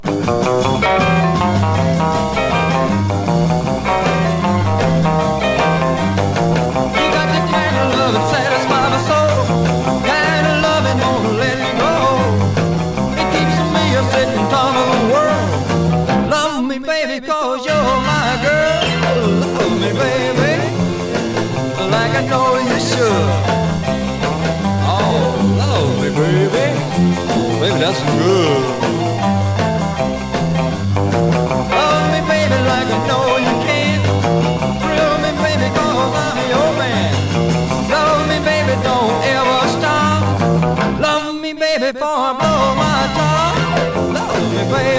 Rockabilly, Rock & Roll　Germany　12inchレコード　33rpm　Mono